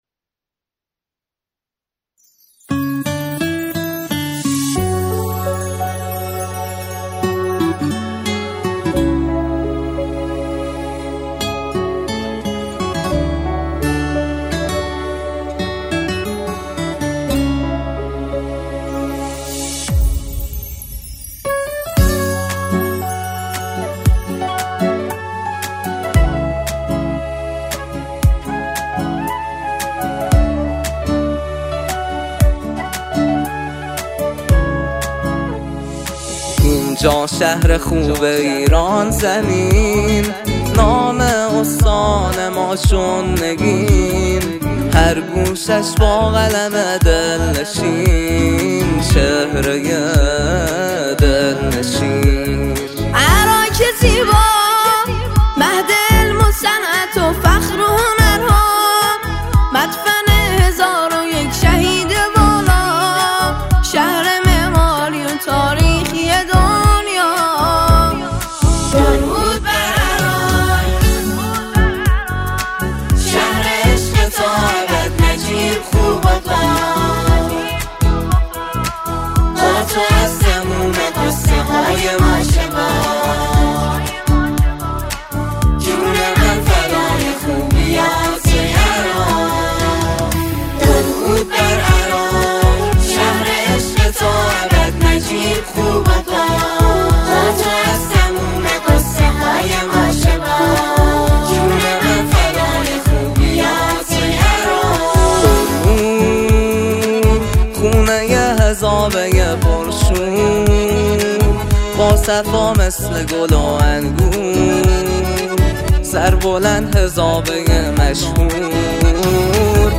اثری شاد، پرشور و سرشار از غرور ملی
ژانر: سرود